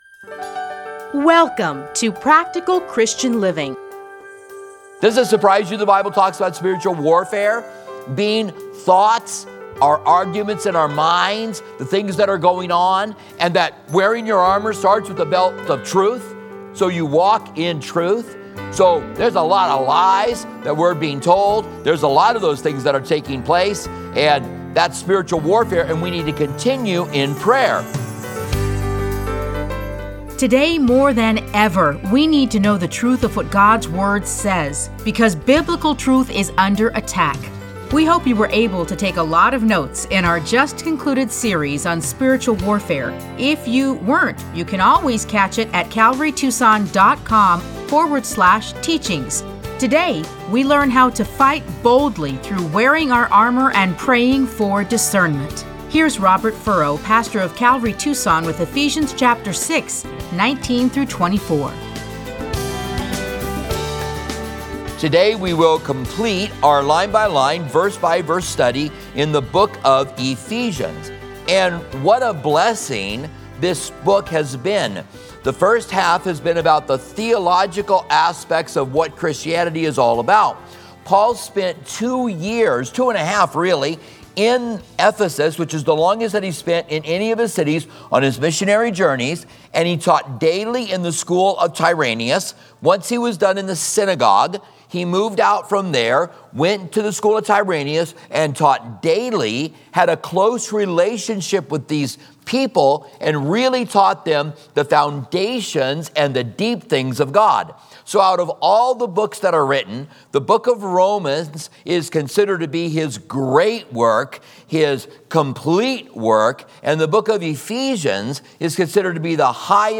Listen to a teaching from Ephesians 6:19-24.